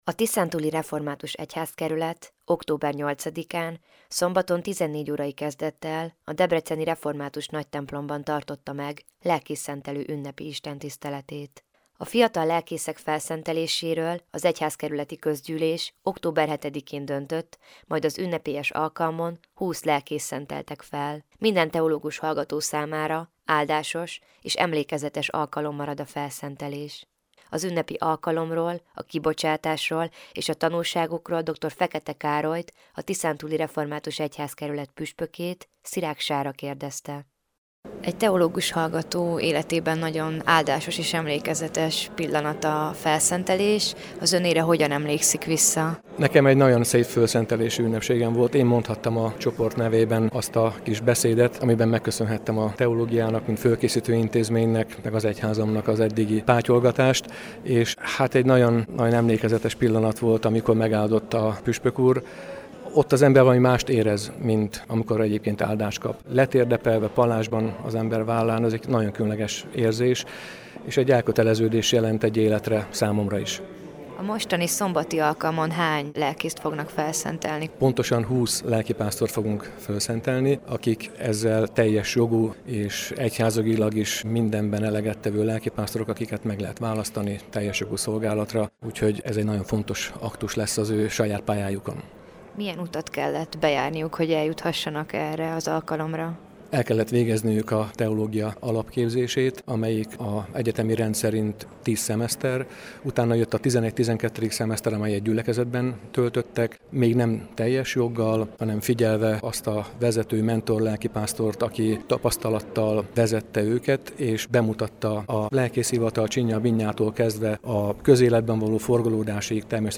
Lelkészszentelő ünnepi istentisztelet a Nagytemplomban - hanganyaggal